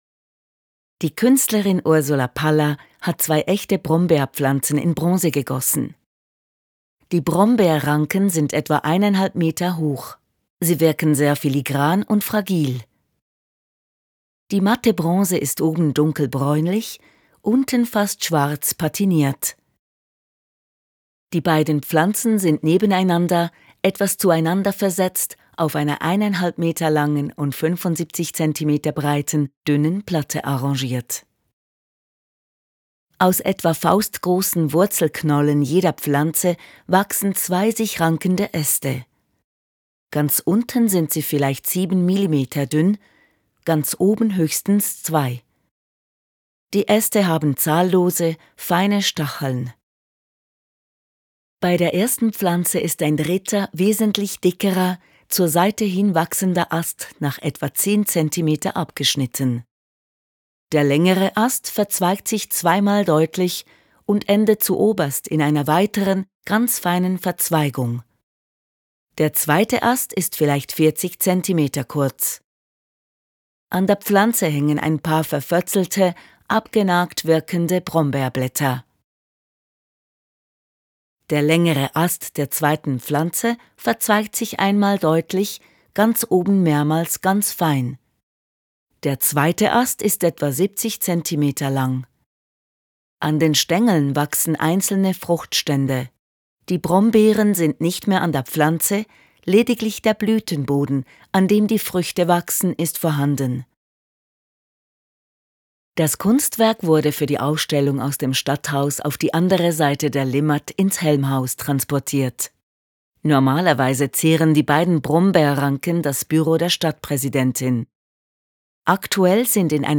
Audiodeskription des Kunstwerks «Brombeerranke»